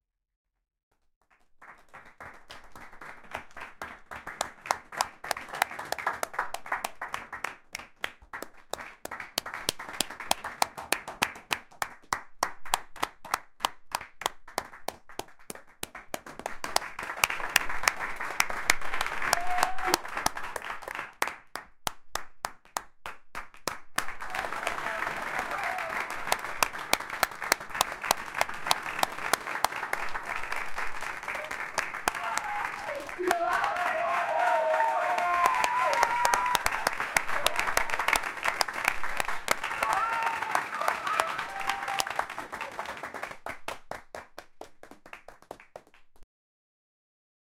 people_clapping.mp3